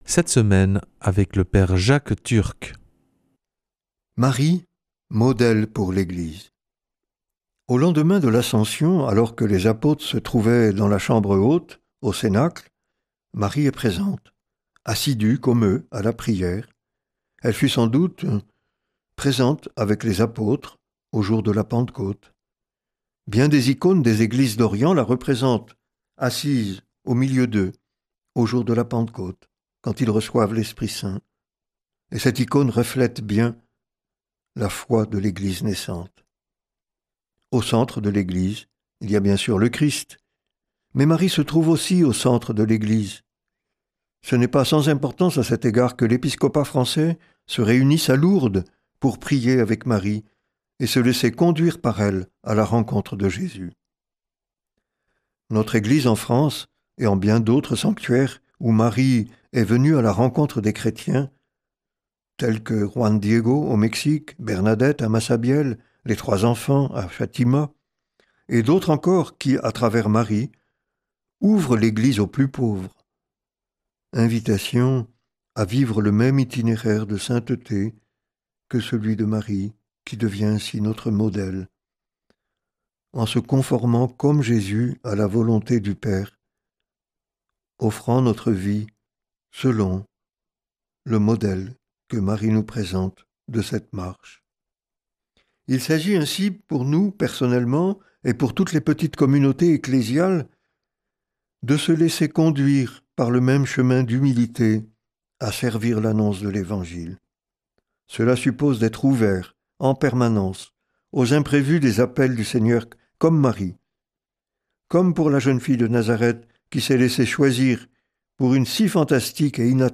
mercredi 15 octobre 2025 Enseignement Marial Durée 10 min